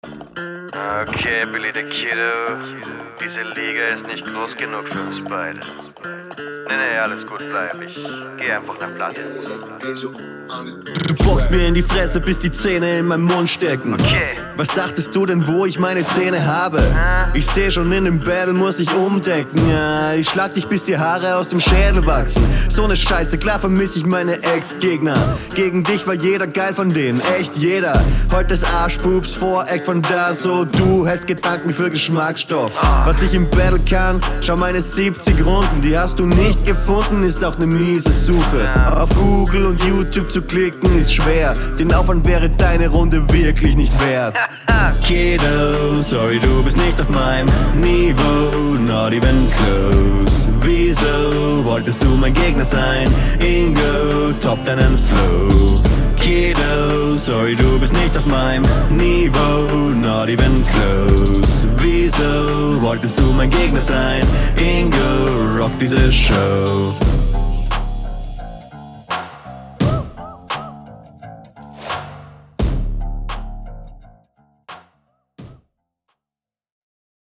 Der Cowboy-Skit am Anfang war auf jeden Fall gut gewählt, gerade in dem Kontext mit …
In puncto Sound und Flow fast identisch zur Vorlage.
hier versteht man dich wieder besser :).